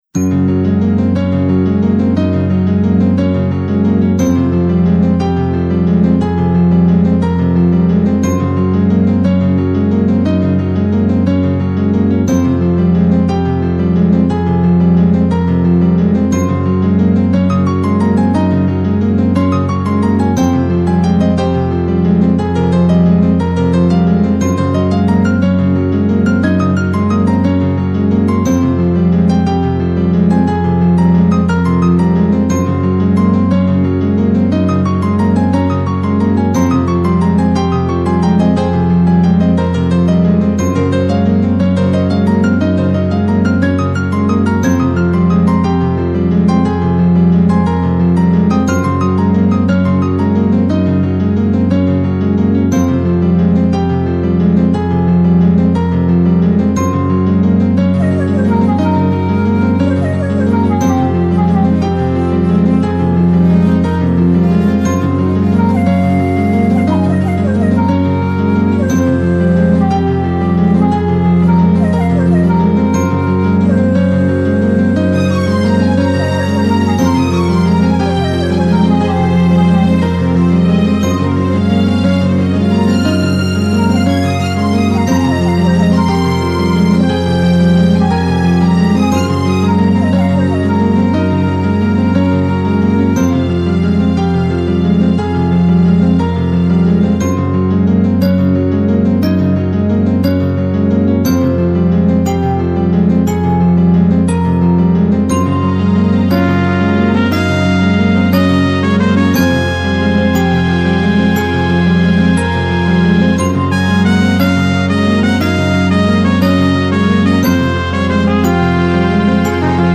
シンセサイザーで曲を作るということを始めてすぐの、実はかなり初期の作品で
尺八とストリングスのカノンがお気に入りです。